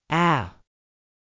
アメリカ英語の母音/æ/は、「ア」と「エ」を合わせたような音です。
母音aeのみの発音.mp3